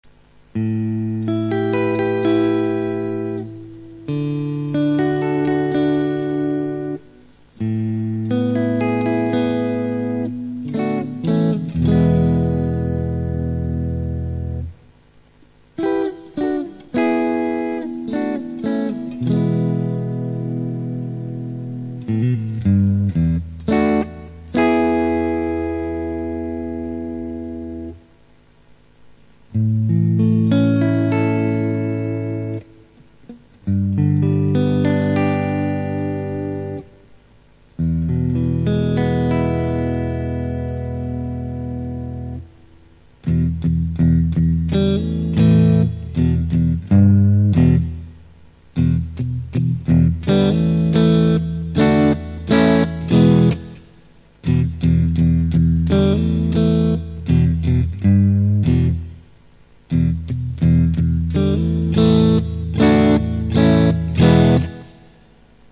Zephyr alnico 3 stratocaster pickup, warm vintage tone
The Zephyr provides warm, smooth 50's style Strat sounds. Light and airy top end, with a softer attack. Firmer mids and a warm bottom end. Overall a great combination of clarity and warmth.